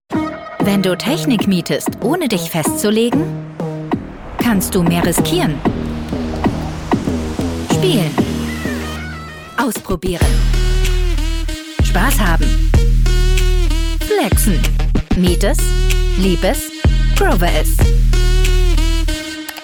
Female
Microphone: Neumann TLM103, Rode NT1 A